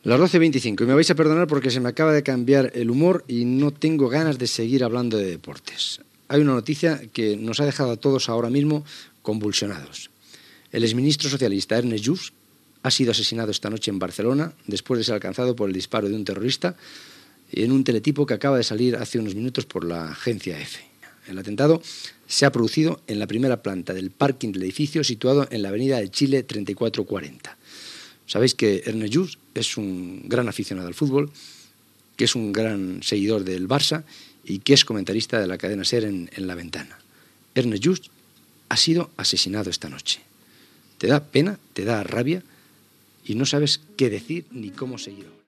Hora (00:25), interrupció del programa per anunciar l'assassinat a Barcelona del polític Ernest Lluch poca estona abans
Esportiu